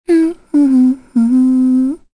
Kirze-Vox_Hum_kr.wav